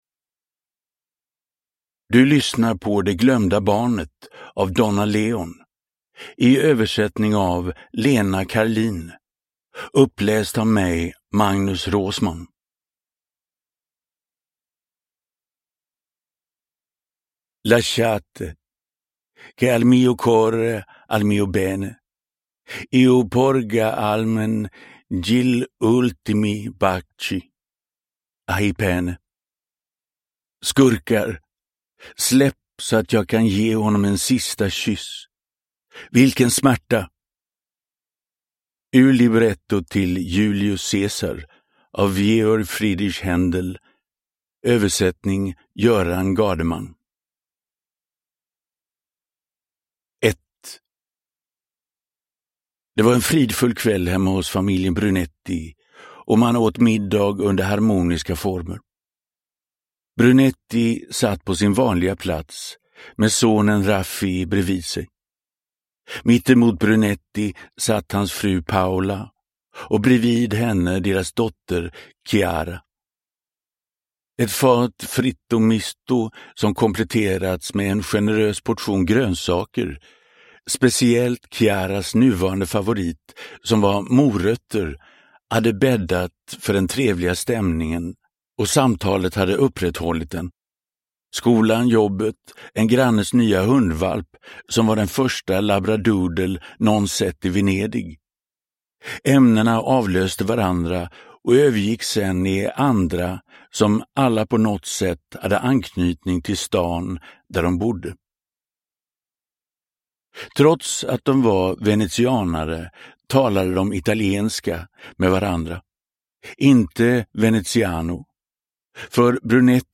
Det glömda barnet – Ljudbok – Laddas ner